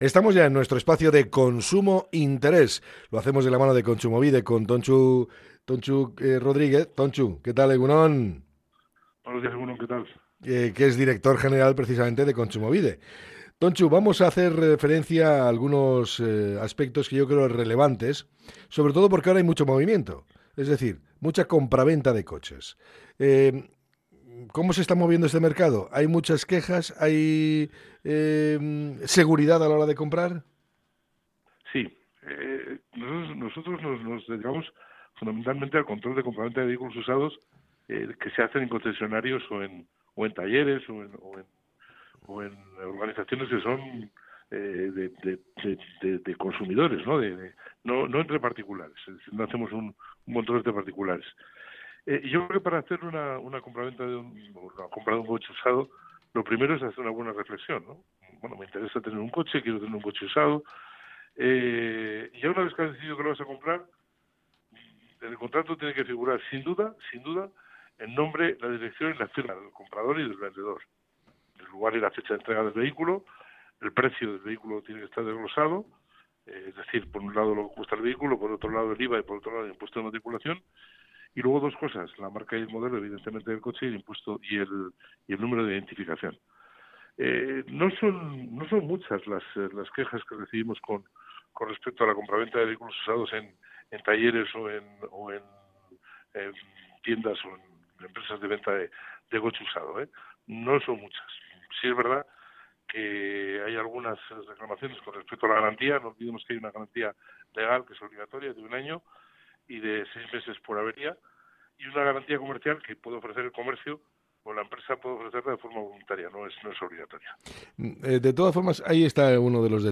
Tontxu Rodríguez, director general del Instituto Vasco de Consumo, nos da algunas pautas a seguir a la hora de comprar un vehículo